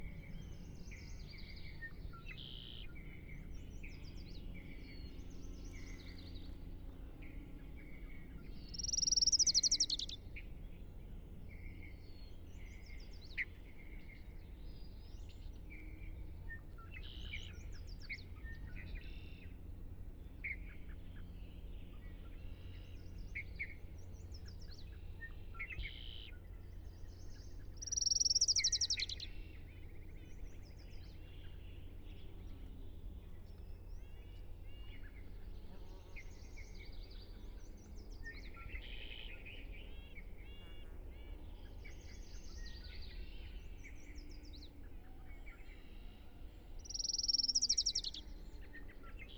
Soundscapes
A warbler in Carman Valley, CA https